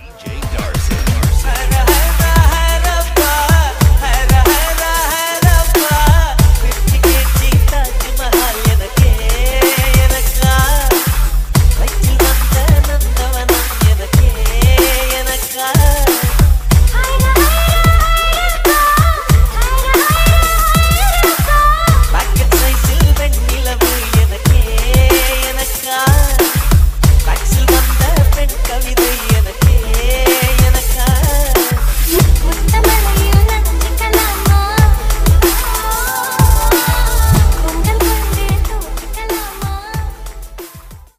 Category: Tamil Ringtones